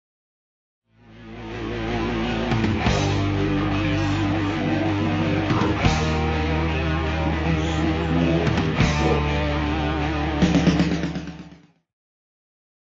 Genere: hard rock
Rovesciato
Incomprensibile